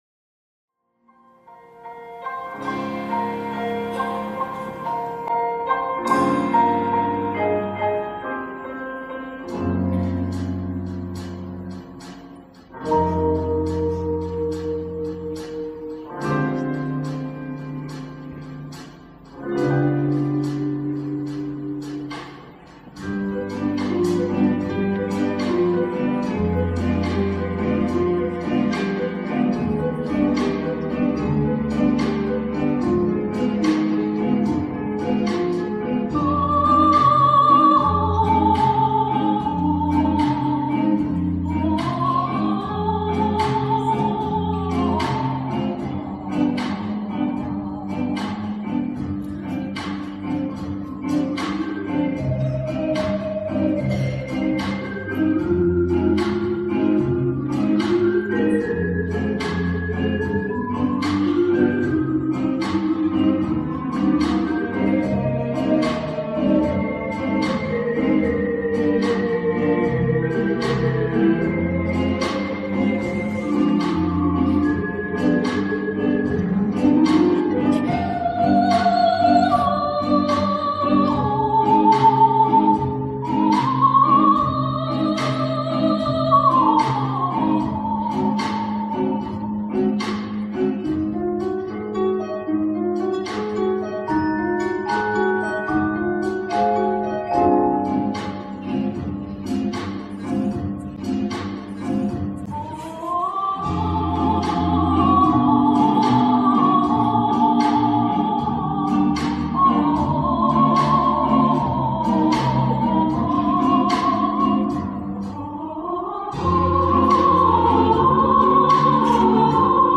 Кто-то записывал на мобильник из зала.
Качество, сами понимаете, какое. Но зато целое призведение, с вокалом, в исполнении оркестра и практически не отличающееся от оригинала.
Пришлось немного потрудиться, чтобы привести скачанный файл в порядок: приглушить шум зала, разговоры и пр.